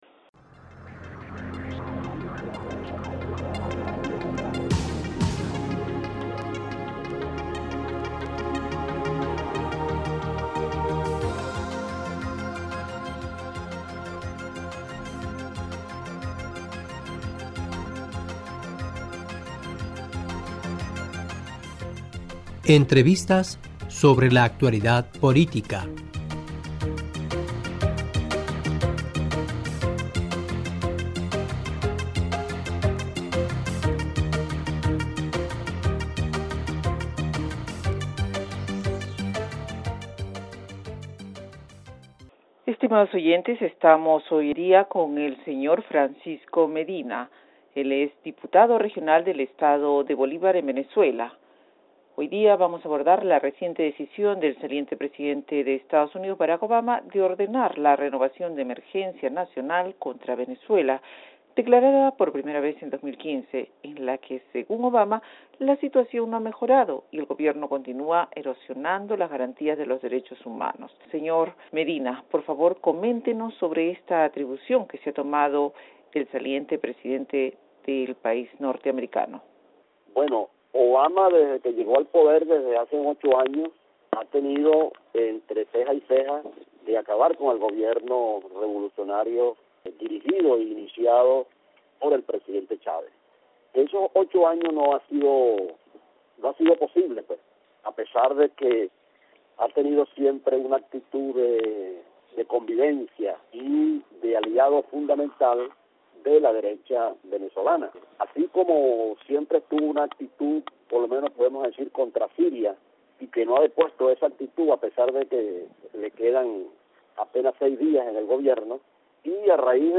E: Estimados oyentes estamos con el Francisco Medina, diputado regional del estado Bolívar de Venezuela.